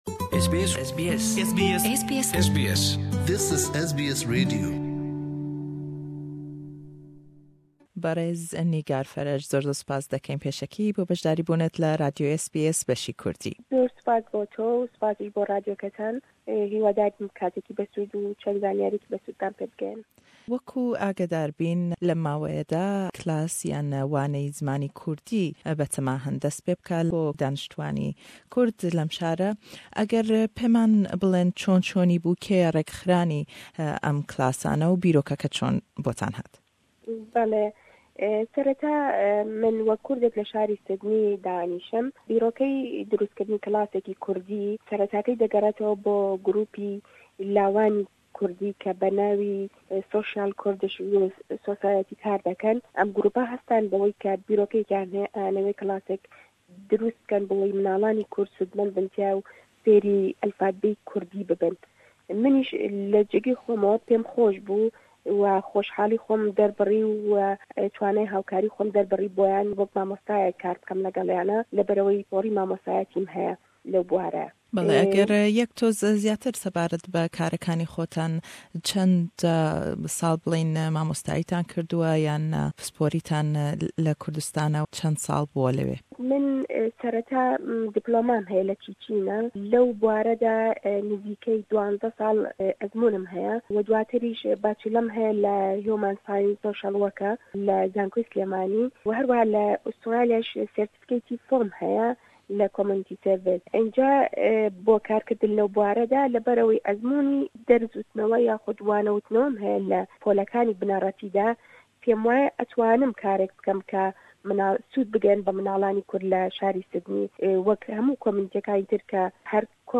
le em hevpeyvîne da